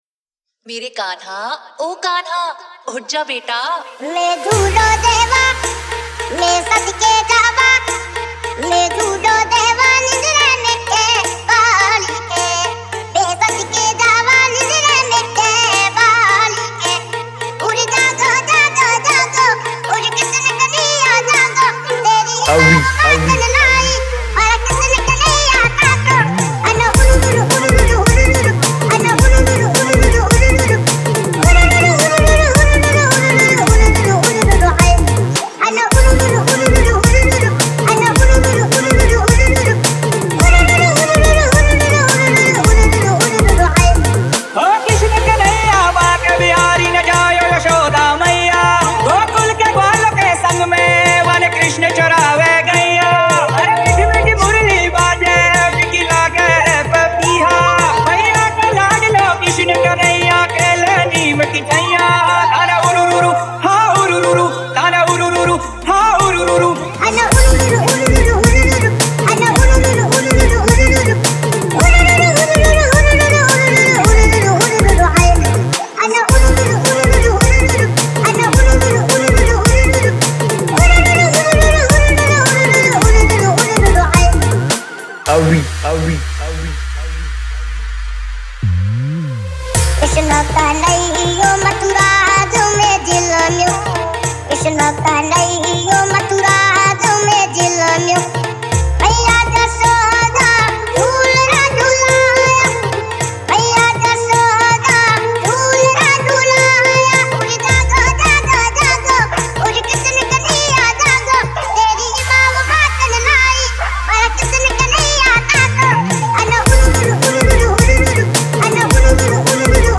Marathi Sound Check 2025